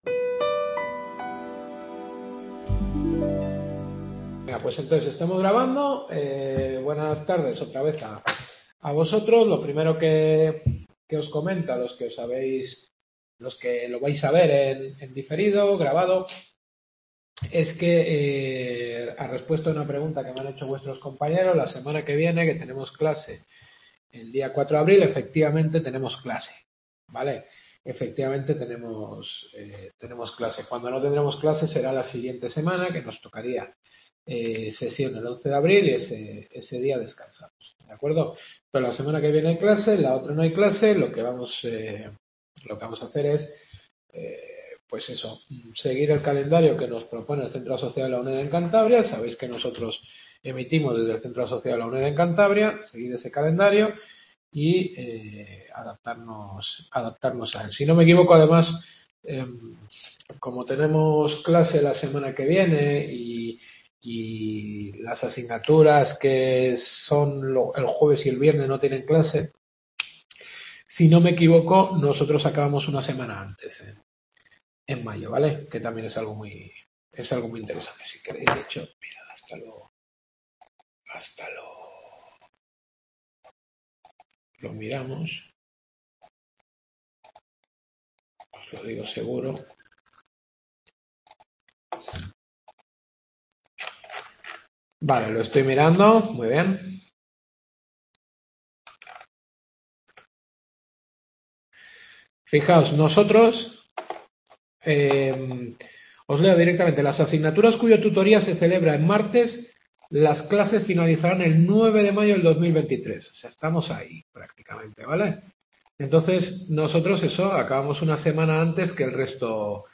Derecho Administrativo Europeo. Séptima Clase.